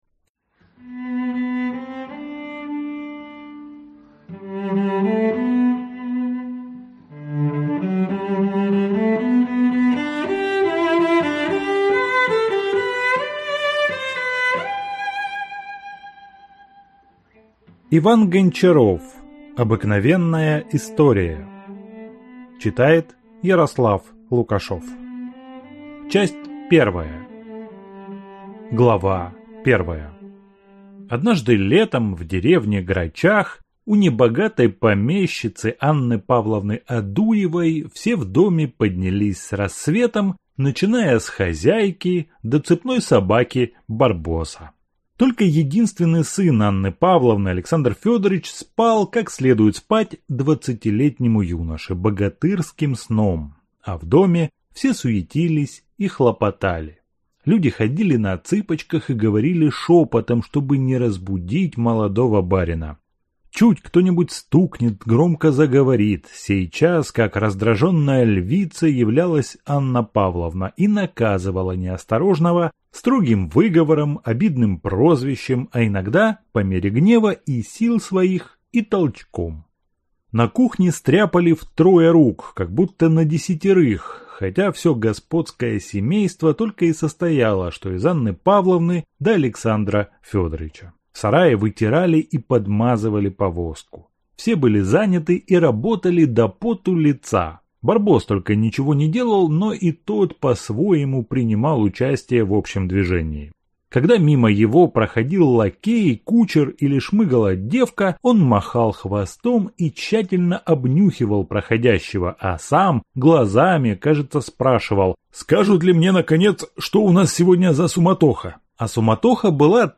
Аудиокнига Обыкновенная история | Библиотека аудиокниг